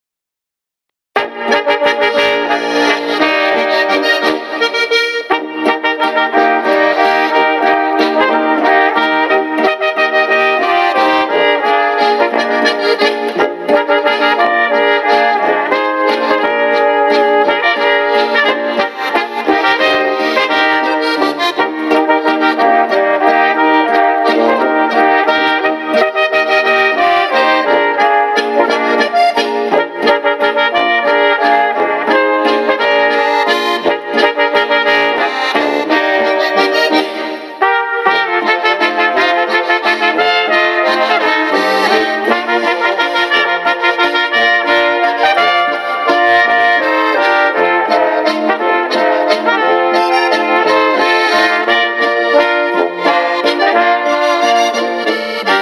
Gattung: Tanzlmusi
Besetzung: Volksmusik Tanzlmusik